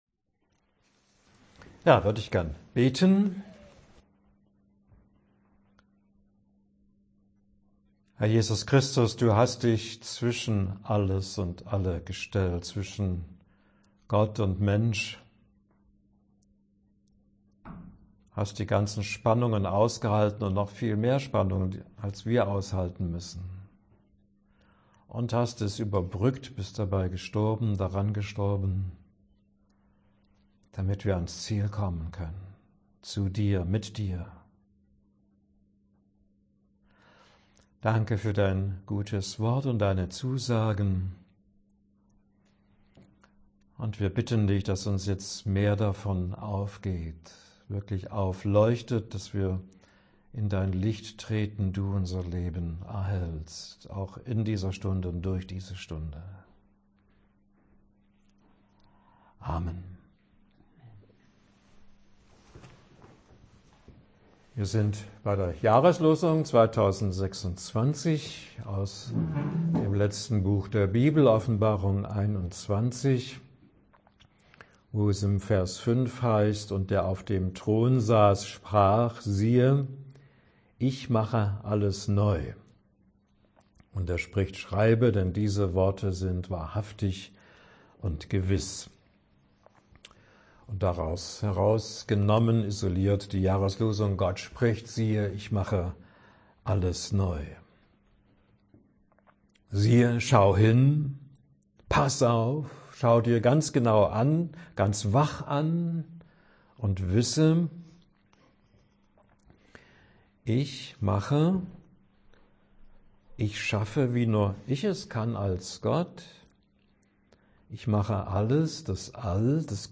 Länge des Vortrages: 63 Minuten